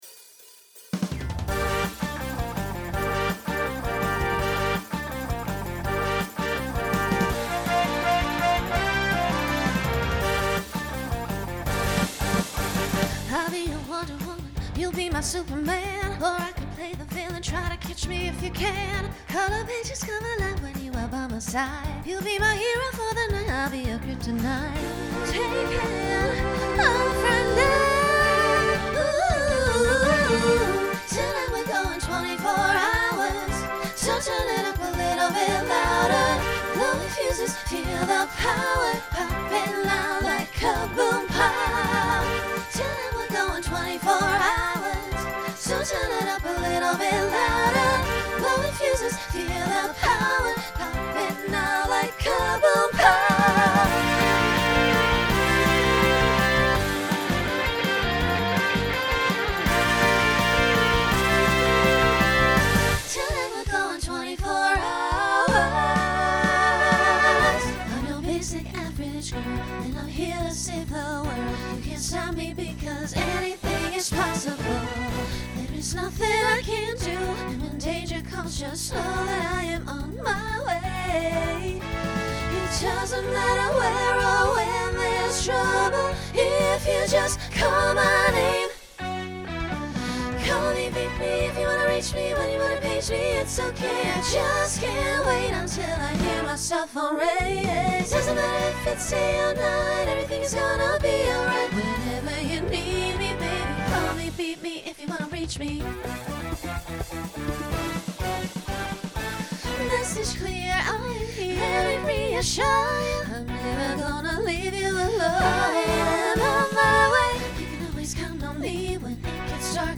Broadway/Film , Pop/Dance Instrumental combo
Voicing SSA